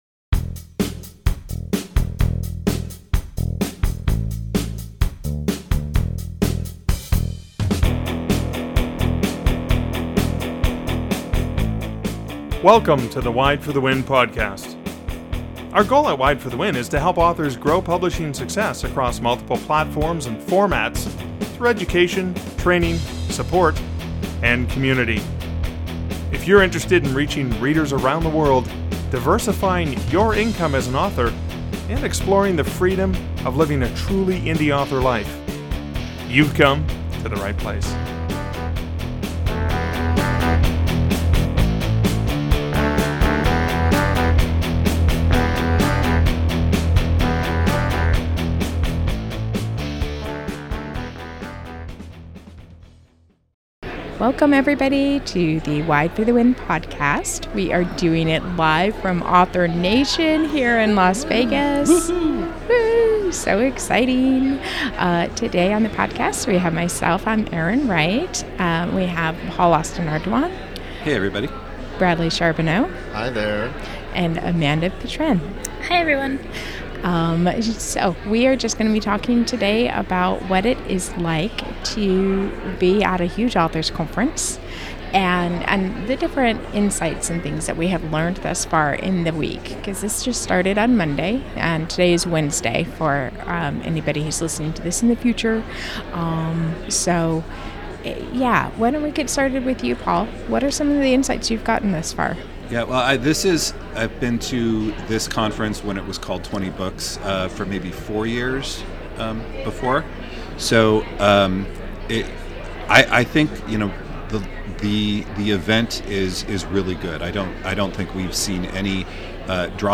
WFTW - 009 - Live From Author Nation 2024